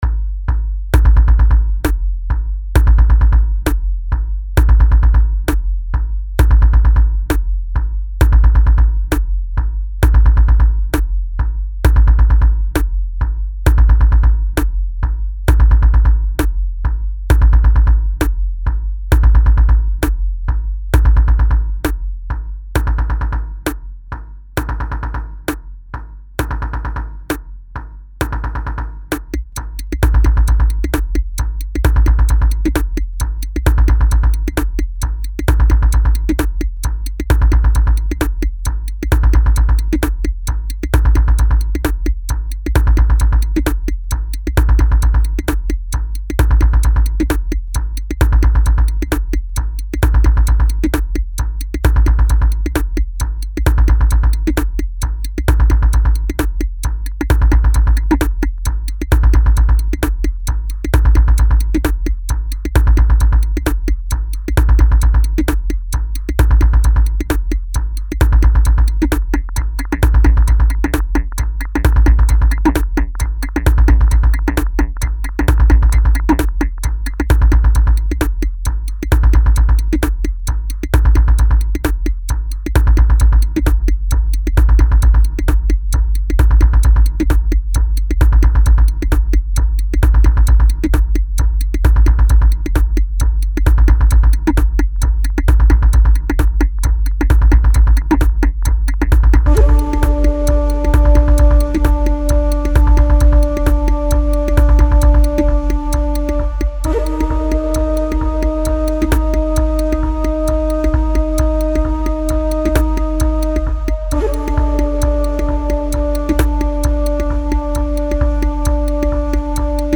Musikstil: Electronica / Dance Music